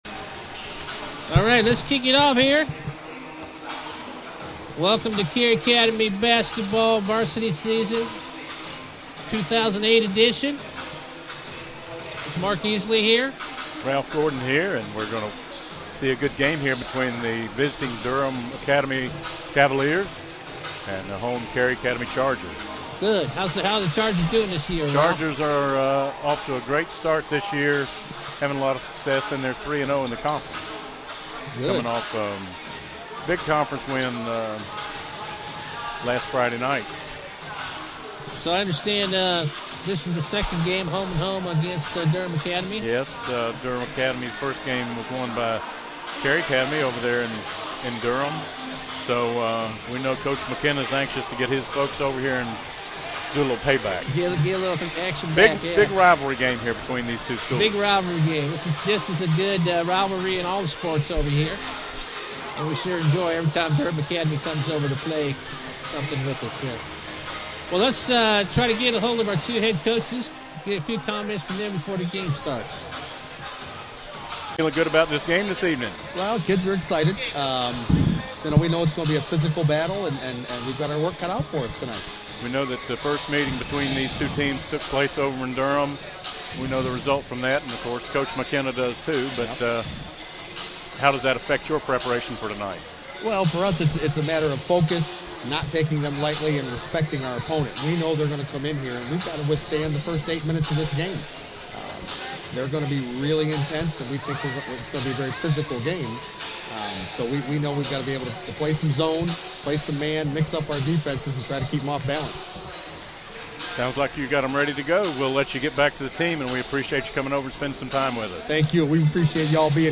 Play-by-Play Audio